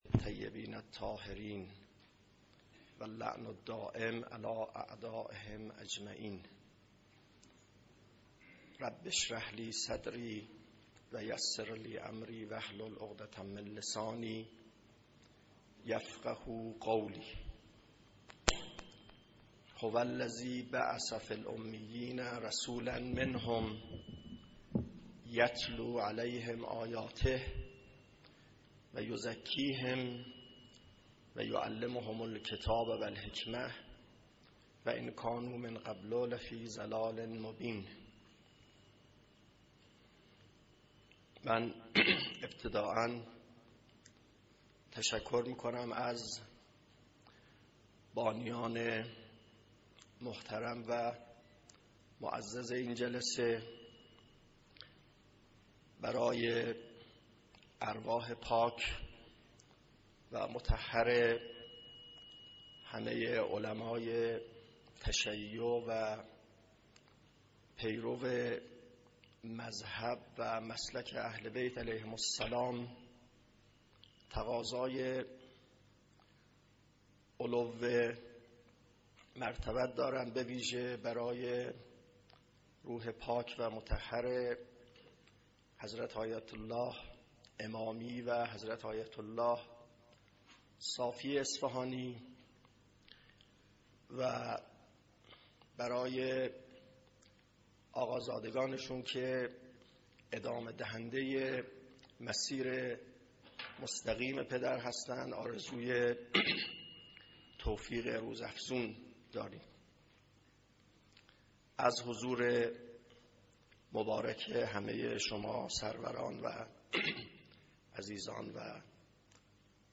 سخنرانی
در اصفهان پیرامون فلسفه و عرفان